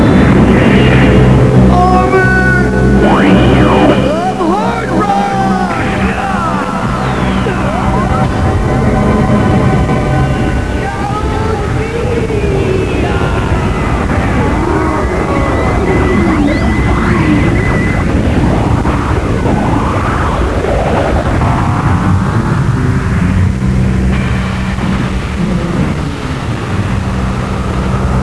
Kento summoning the armor of Hardrock